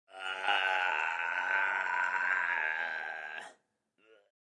Sound Effects
Weird Zombie Moan